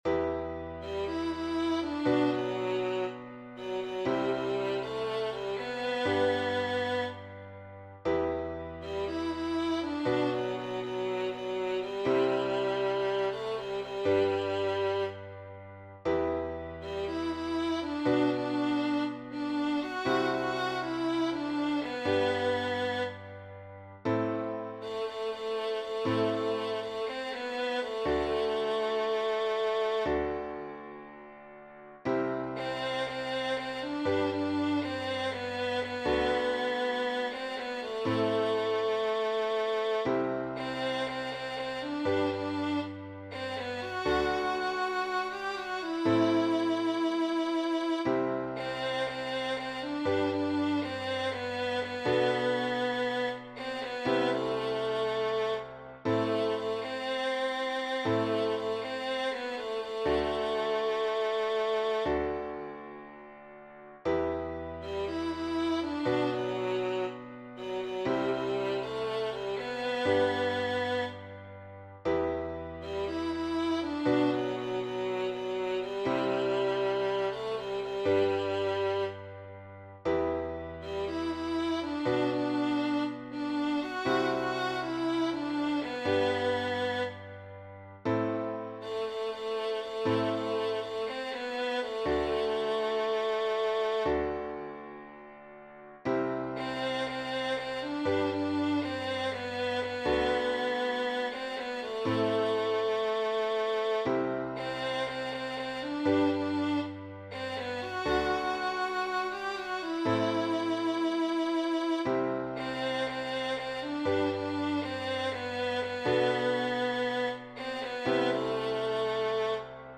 Hymn composed by